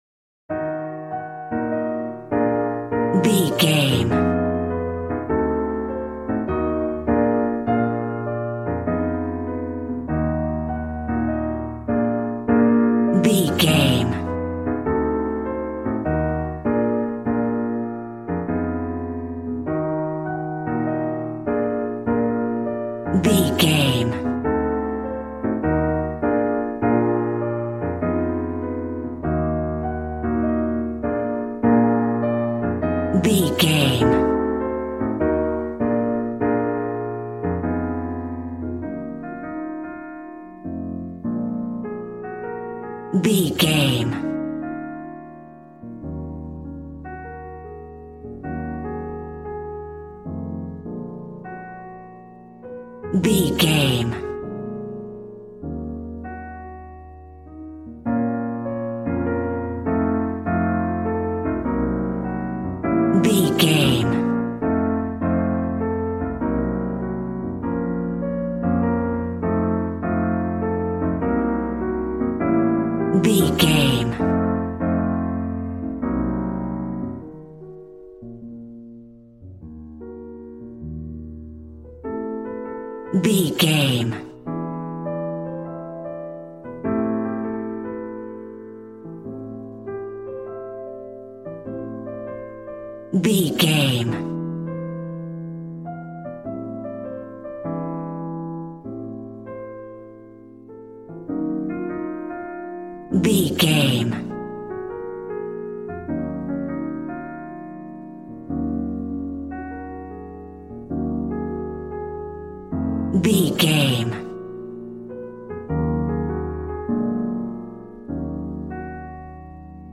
Smooth jazz piano mixed with jazz bass and cool jazz drums.,
Ionian/Major
B♭
piano